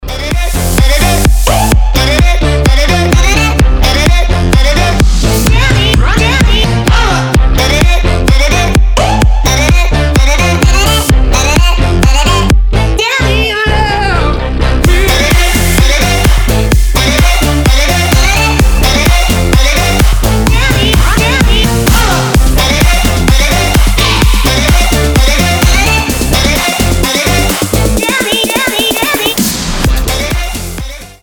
• Качество: 320, Stereo
dance
Electronic
EDM
future house
забавный голос
house